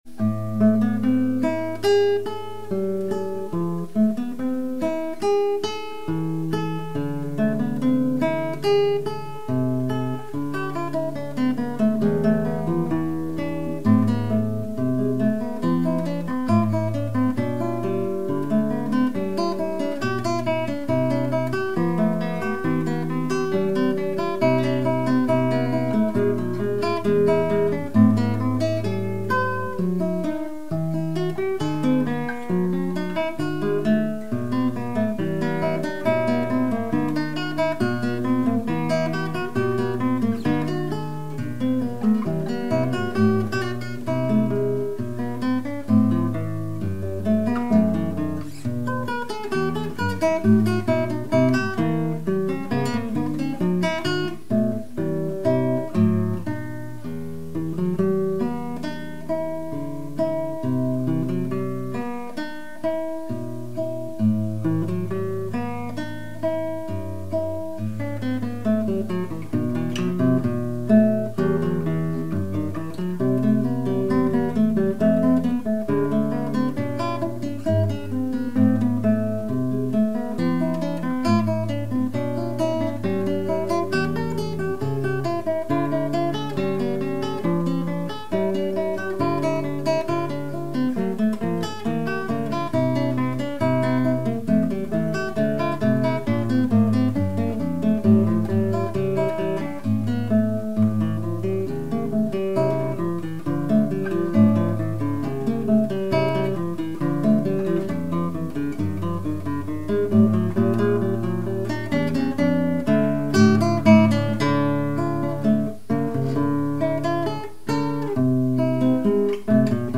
You might be interested in a stereo recording my son made a few weeks ago.
The mics were a pair of Behringer C2 capacitors setup as a crossed pair.